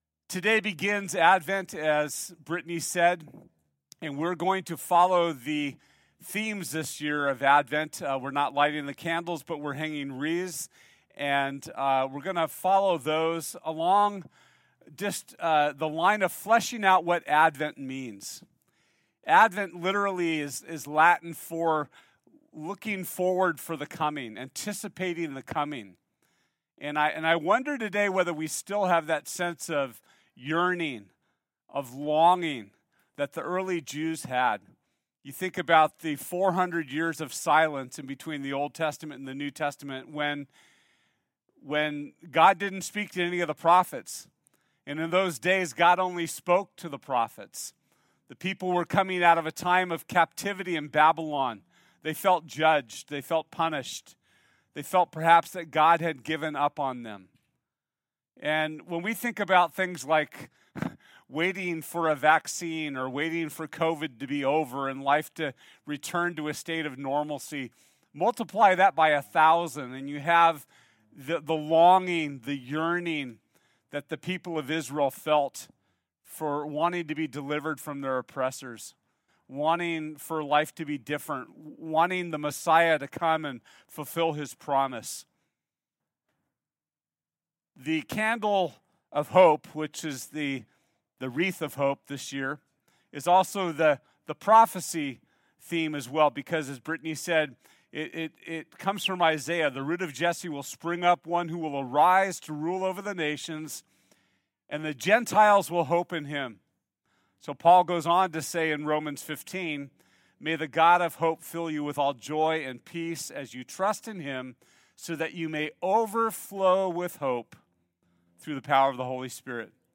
COME Service Type: Sunday Join us for our new Christmas series titled “COME!”